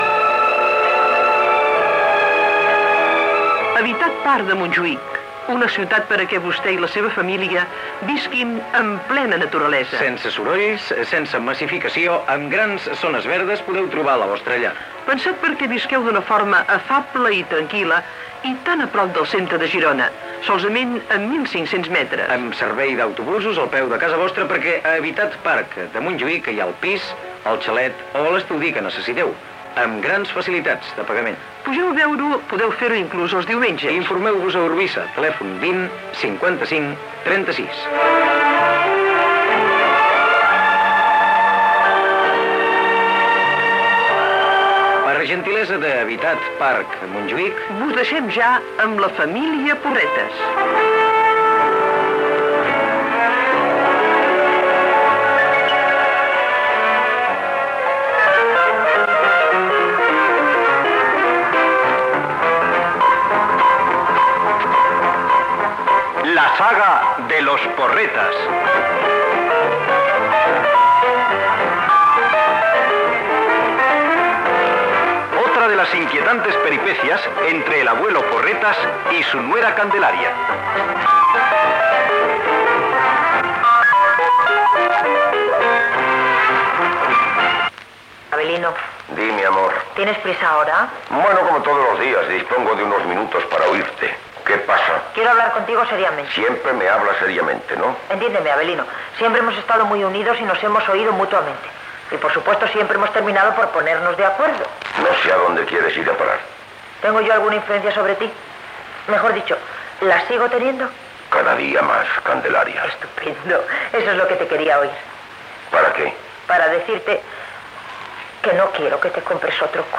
careta del programa, diàleg entre Candelaria, Abelino i Segismundo sobre la compra d'un nou cotxe.
Gènere radiofònic Ficció